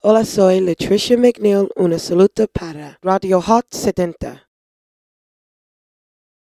035b267d1ae394b6a758468cafdbed2ecbccba0e.mp3 Títol Ràdio Hot 70 Emissora Ràdio Hot 70 Titularitat Privada local Descripció Salutació de la cantant nord-americana, Lutricia McNeal.